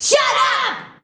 SHUT UP.wav